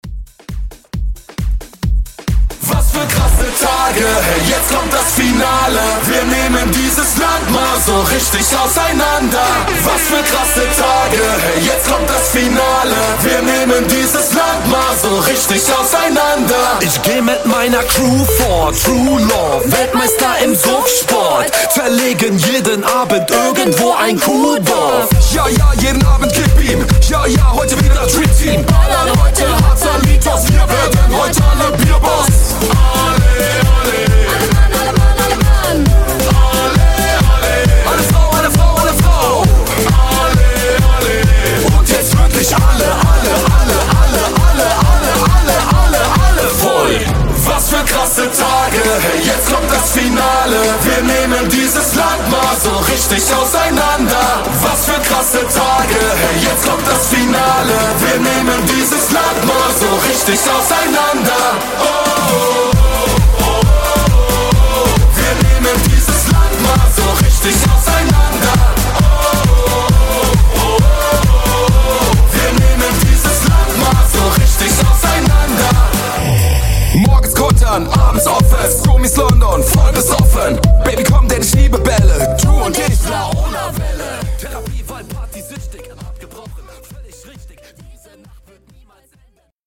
Genres: 90's , RE-DRUM
Clean BPM: 80 Time